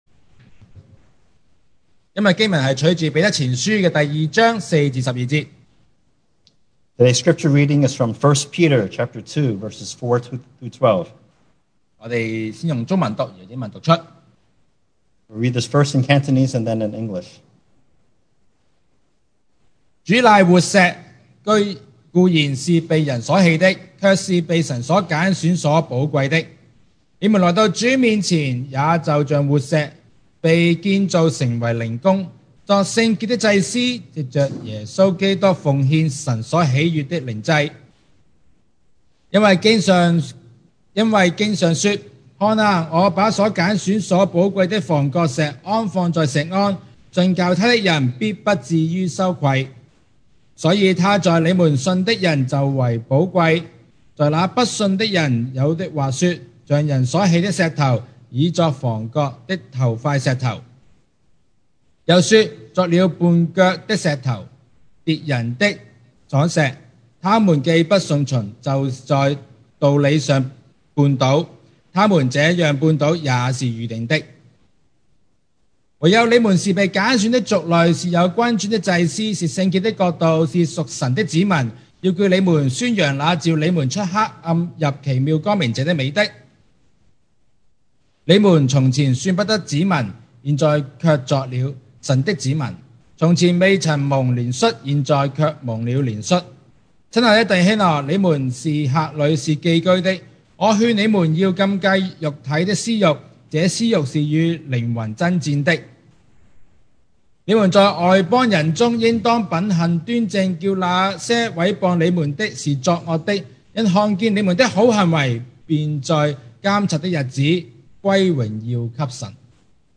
2021 sermon audios
Service Type: Sunday Morning